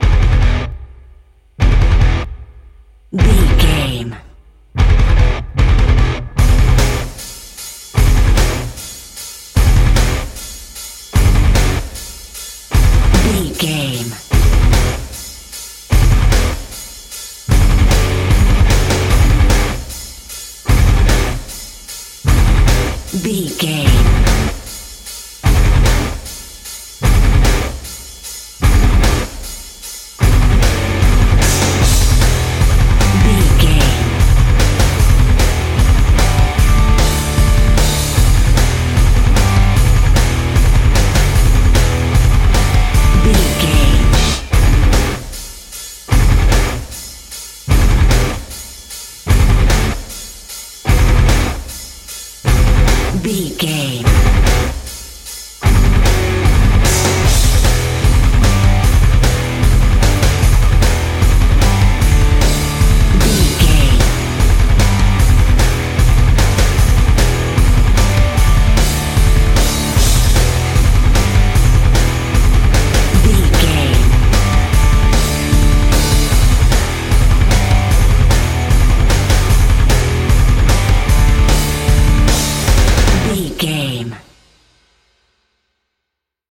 Aeolian/Minor
Fast
hard rock
metal
lead guitar
bass
drums
aggressive
energetic
intense
nu metal
alternative metal